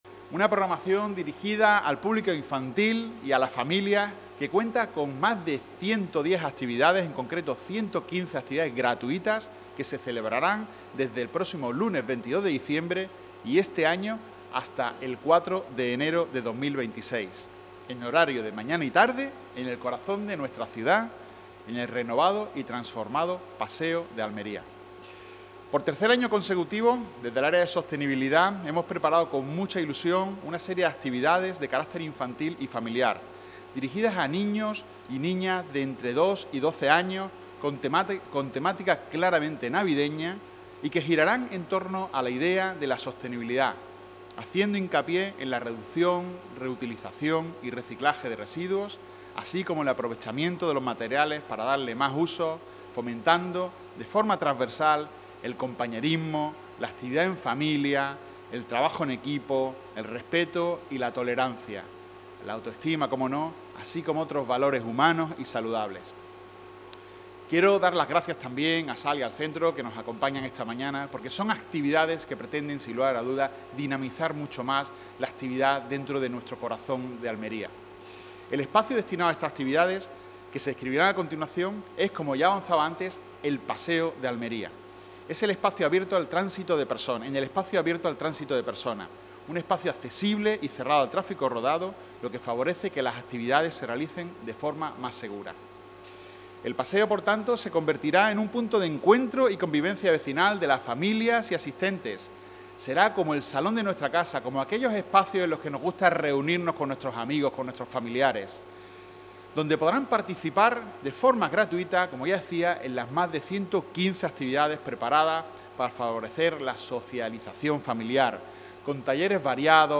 El concejal de Sostenibilidad Medioambiental y Energética, Antonio Urdiales, presenta la iniciativa ‘Un Paseo en Navidad: talleres, juegos y animación’, que se desarrollará del 22 de diciembre al 4 de enero